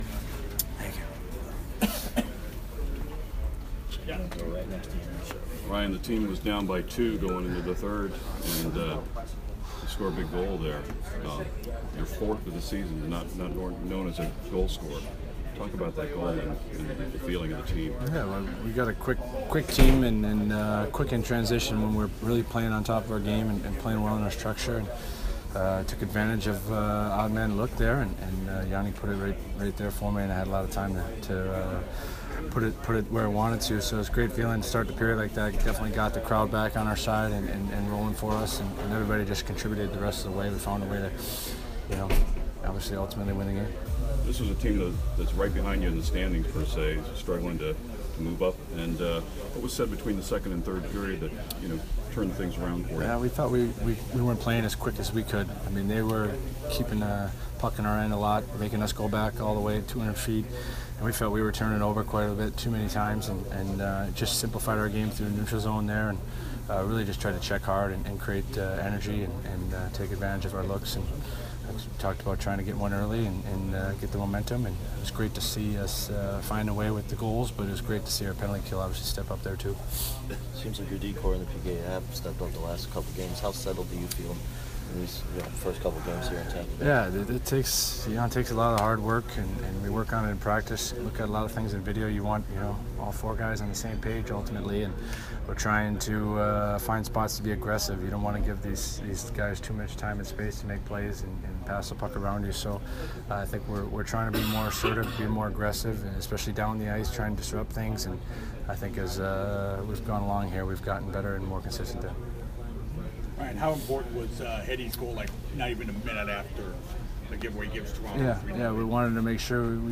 Ryan McDonagh post-game 3/20